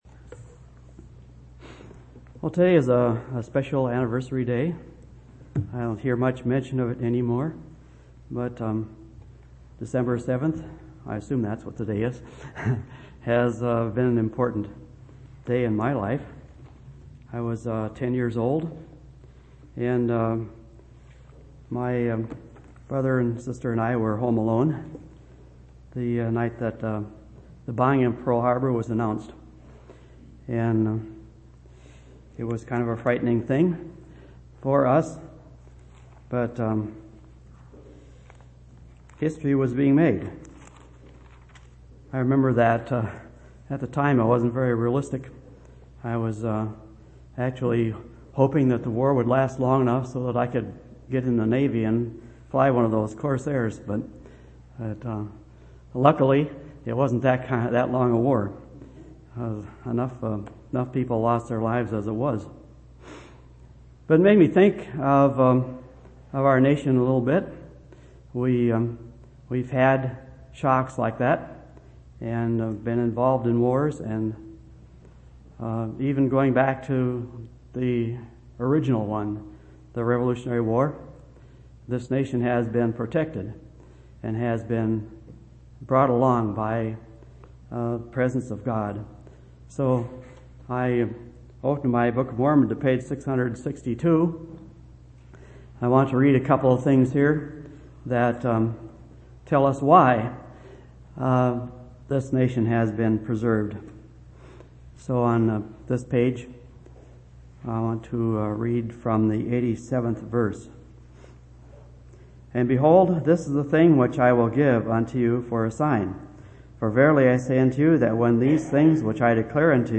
12/7/2003 Location: Temple Lot Local Event